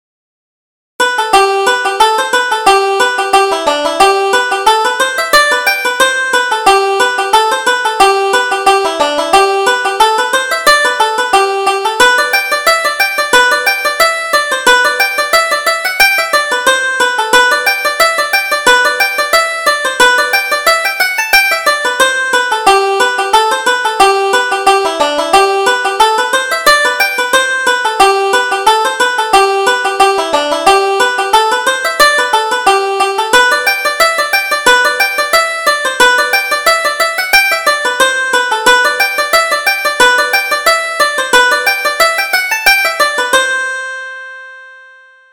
Reel: The Boys of Portaferry